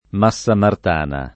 m#SSa mart#na] (Umbria); qualche volta con grafia unita o unibile: Massaciuccoli, Massarosa, Massa Lubrense o Massalubrense — der. da quest’ultimo, a Napoli, il nome di porta di Massa, dove approdavano le feluche da Massa Lubrense — sim. anche i cogn. M., Del M., Di M. — cfr. La Massa